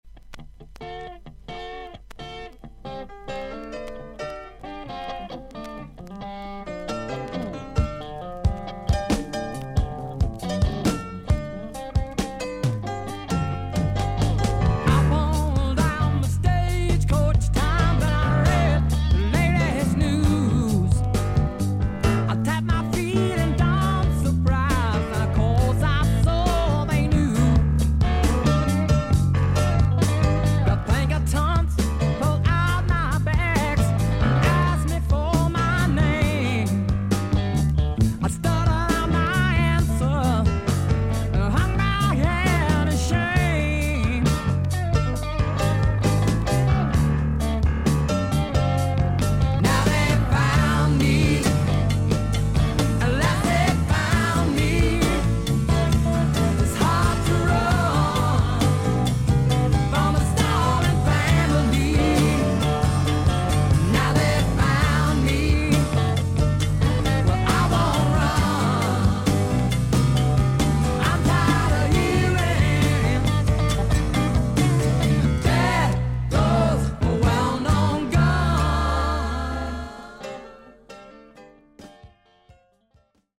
イギリス盤 / 12インチ LP レコード / ステレオ盤
少々軽いパチノイズの箇所あり。クリアな音です。